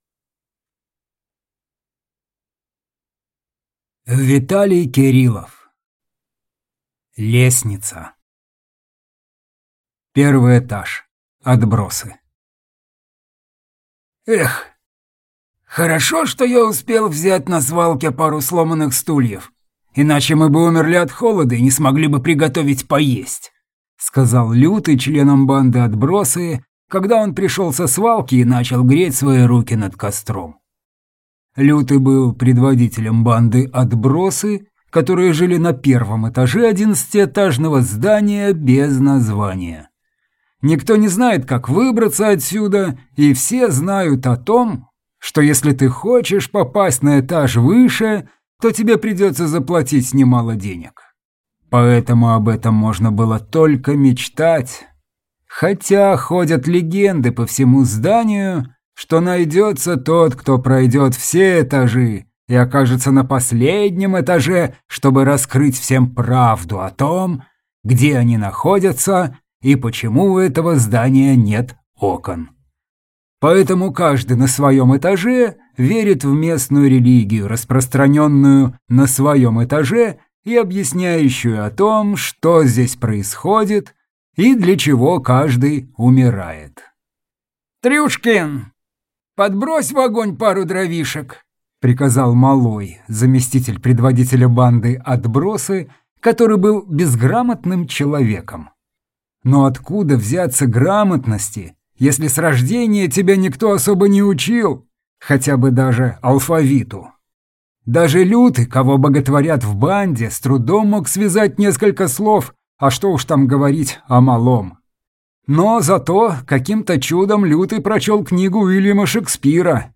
Аудиокнига Лестница | Библиотека аудиокниг